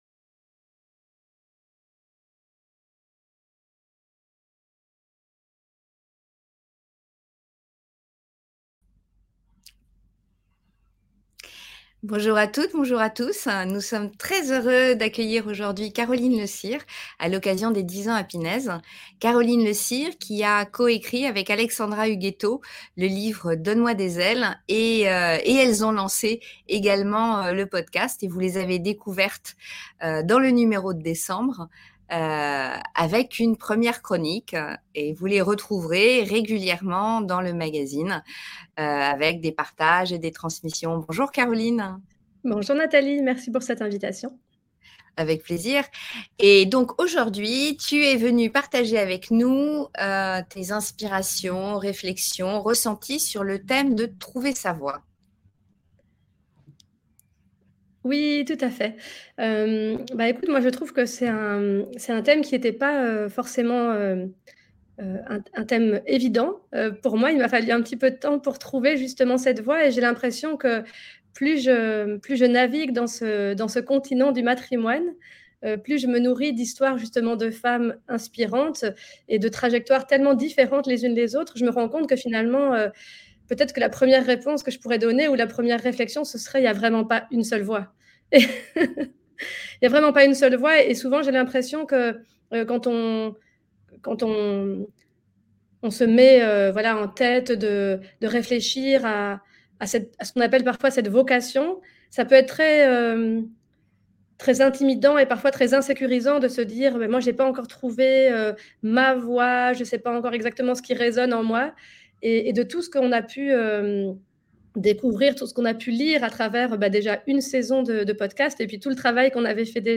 Interview 10 ans - Trouver sa voie